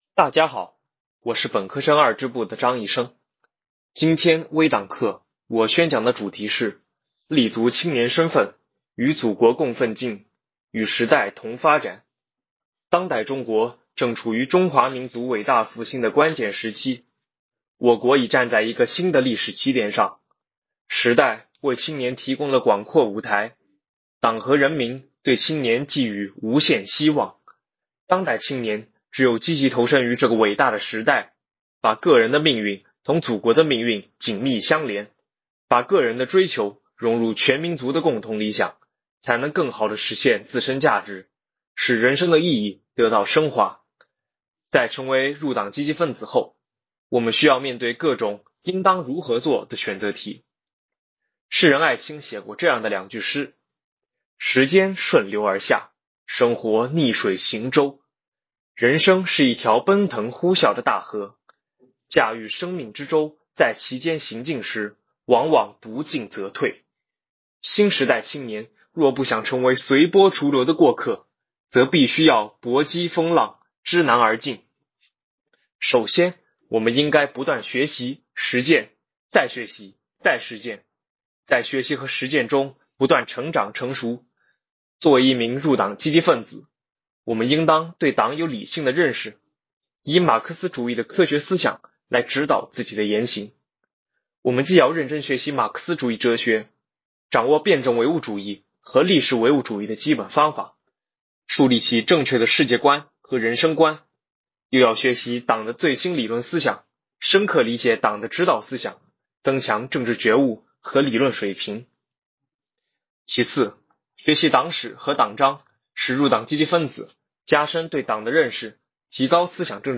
为引导广大教师和学生党员坚定理想信念，厚植爱国情怀，苏州大学计算机科学与技术学院分党校鼓励全体学员人人讲党课，开展“三分钟微党课”特色宣讲活动，展现分党校学员的示范引领作用，把党课融入日常、做在经常，把学习贯彻习近平新时代中国特色社会主义思想不断引向深入。